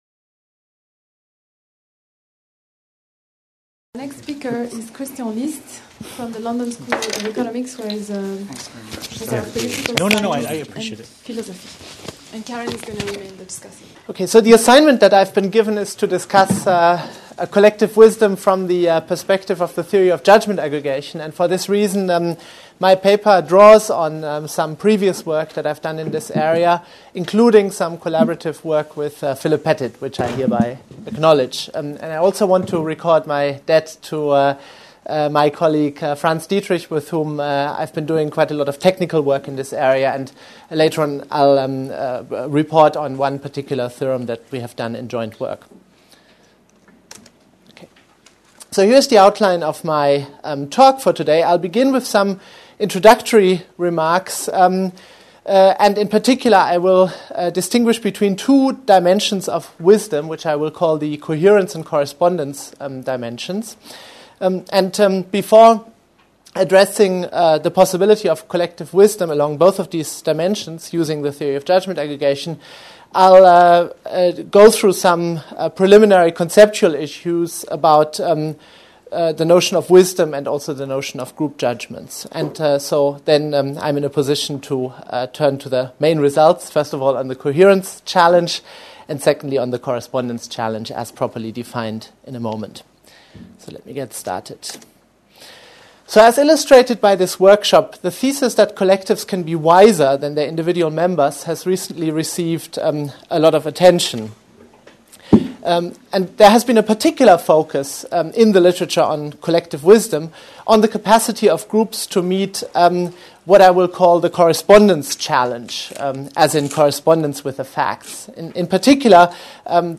La sagesse collective : principes et mécanismes Colloque des 22-23 mai 2008, organisé par l'Institut du Monde Contemporain du Collège de France, sous la direction du Professeur Jon Elster.